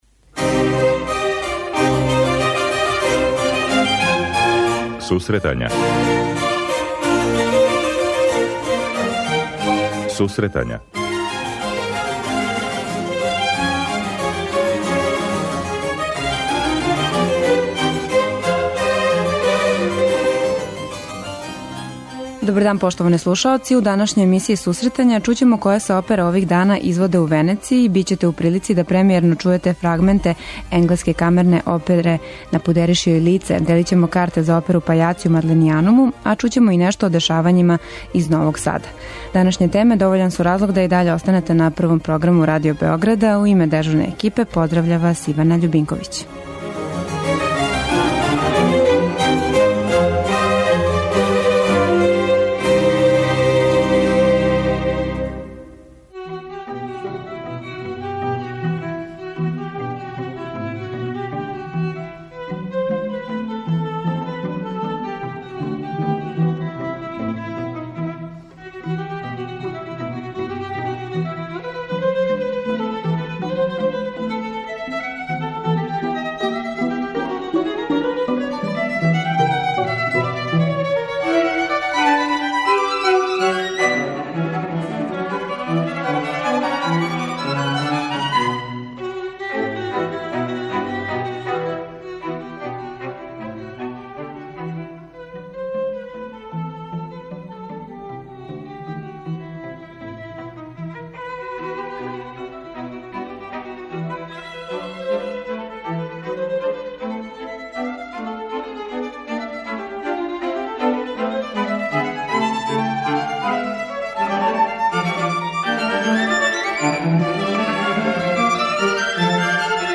Емисија Сусретања ће највећим делом бити посвећена опери 'Powder her face' која је ових дана актуелна у Венецији. Чућемо премијерно фрагменте опере, као и разговоре са извођачима.